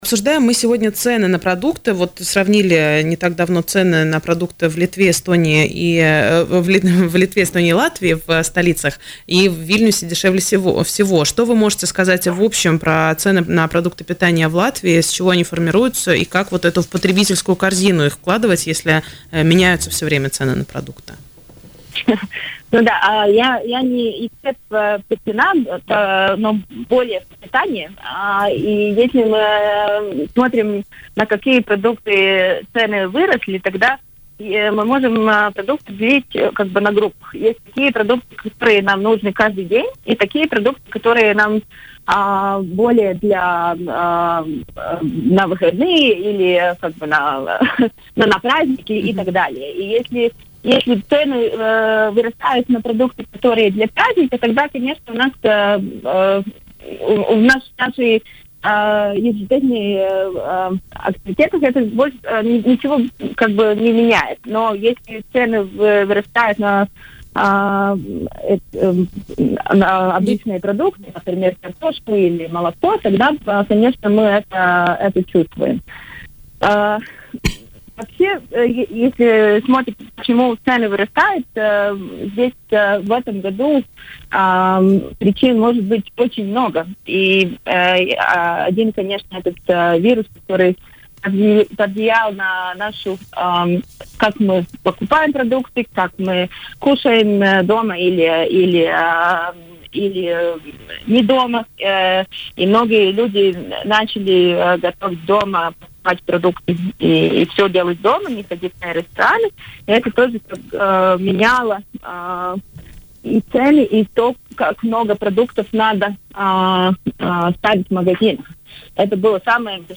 Коронавирус заставил людей внимательнее отнестись к своему питанию. Такое мнение в эфире радио Baltkom высказала исследователь института BIOR и автор